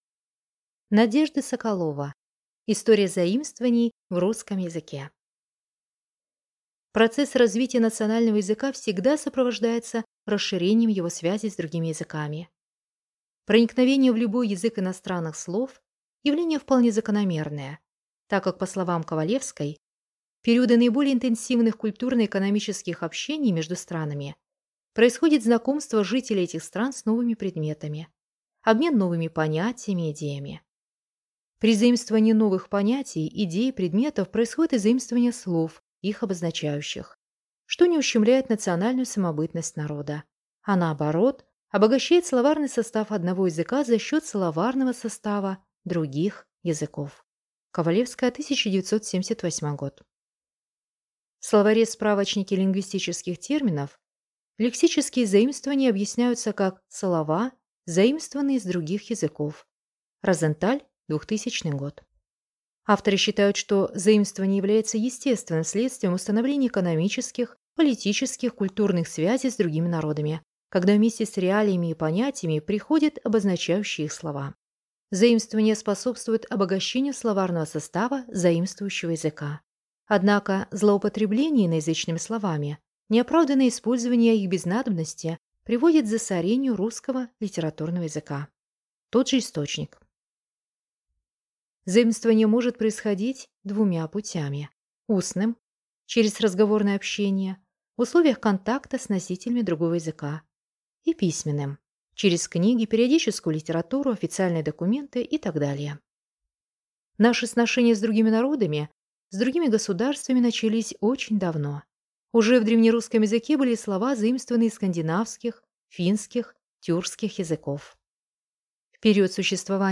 Аудиокнига История заимствований в русском языке | Библиотека аудиокниг